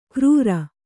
♪ krūra